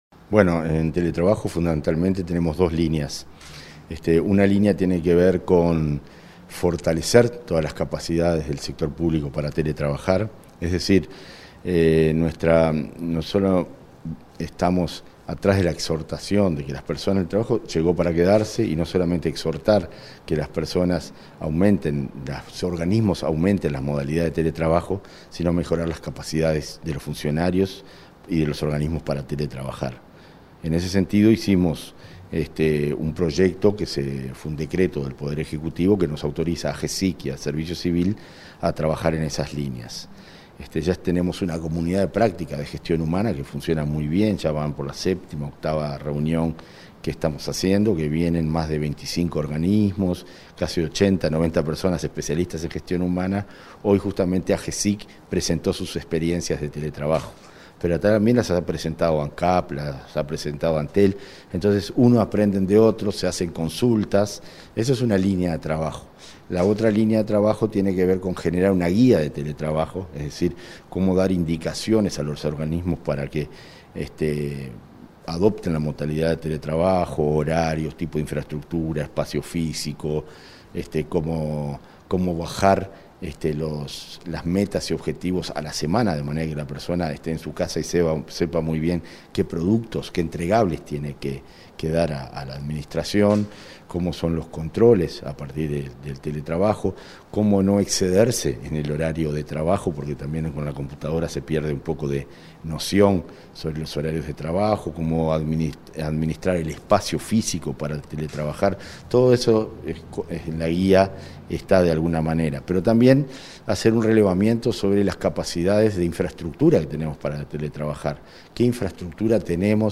Entrevista con el director de la Oficina Nacional de Servicio Civil, Conrado Ramos